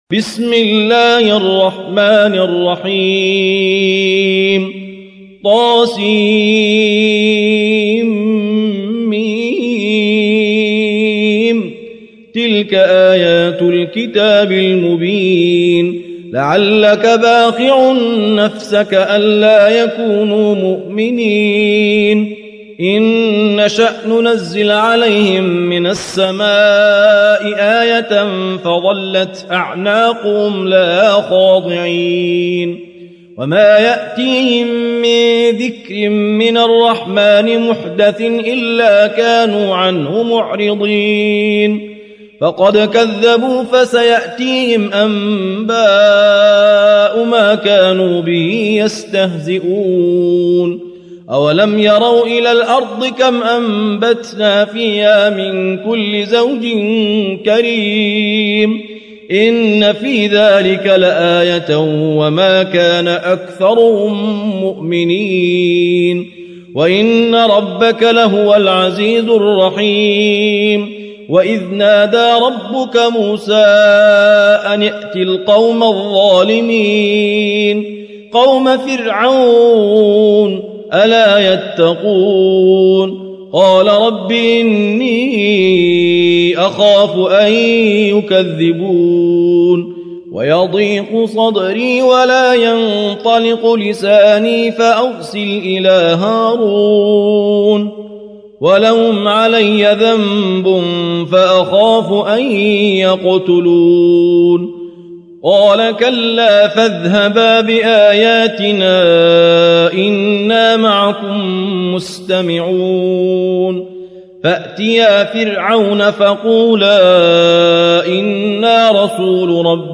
التصنيف: تلاوات مرتلة